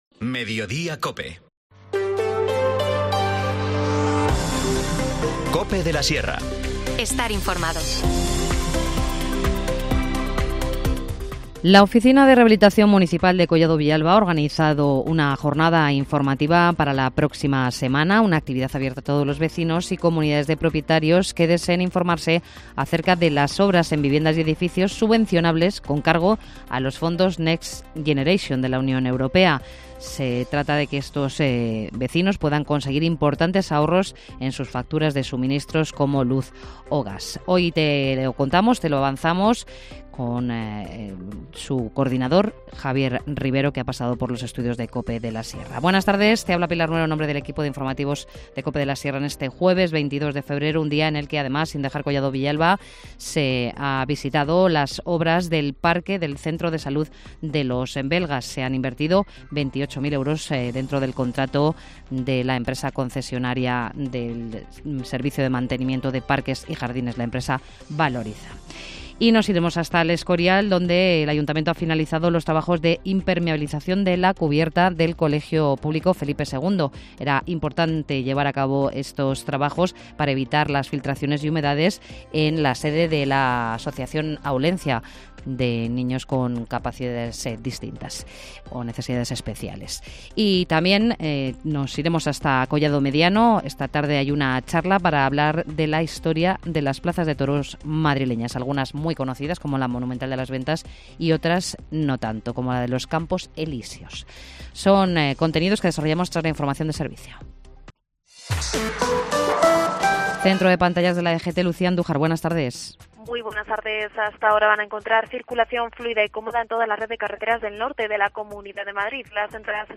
Informativo | Mediodía en Cope de la Sierra, 22 de febrero de 2024